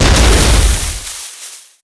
launcher_explode1.wav